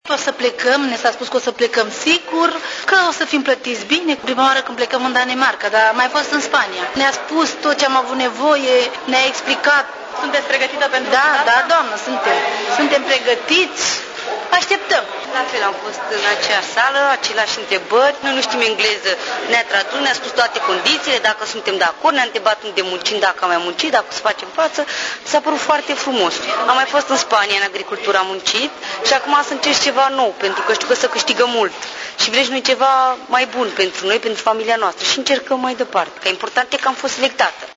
În urma interviului, candidaţii au aflat pe loc dacă sunt sau nu acceptaţi. Printre norocoşi s-au regăsit şi două femei din Călăraşi, obişnuite cu munca la câmp şi care aşteaptă acum să plece pentru prima dată în Danemarca: